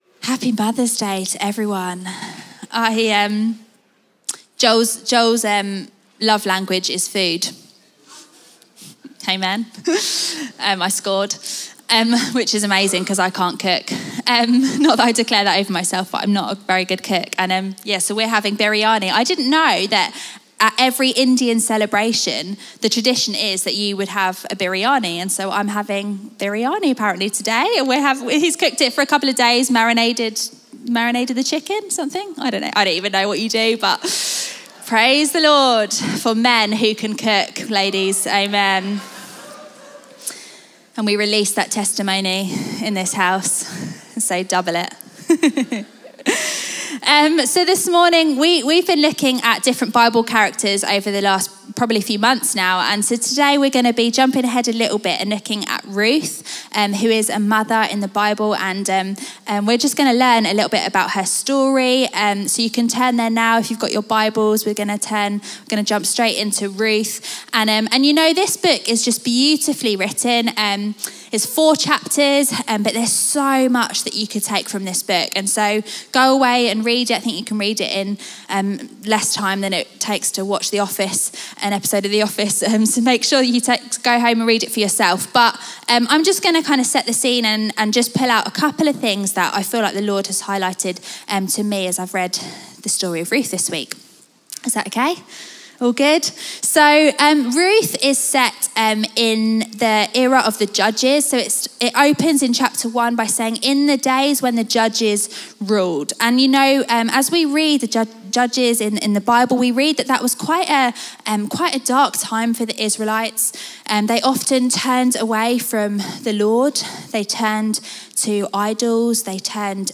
Chroma Church - Sunday Sermon The Lord our redeemer Mar 30 2023 | 00:24:14 1x 00:00 / 00:24:14 Subscribe Share RSS Feed Share Link Embed